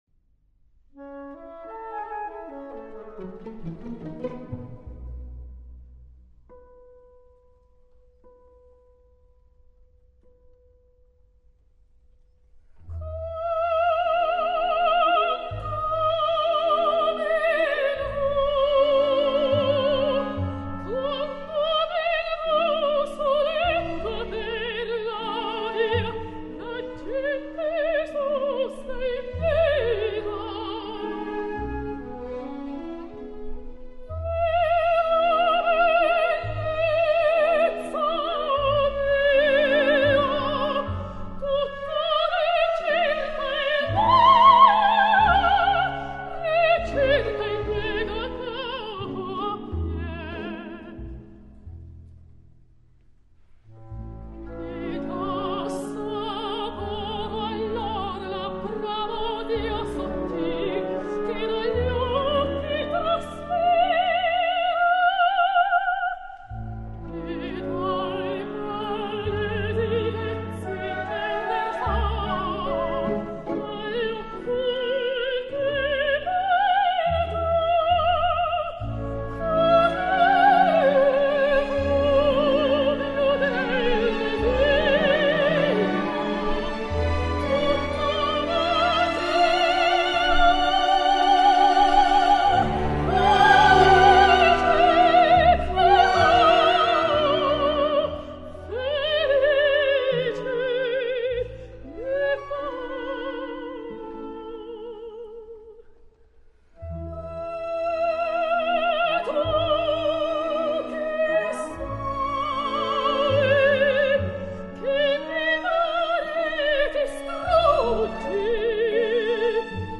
per soprano (ad lib.) e banda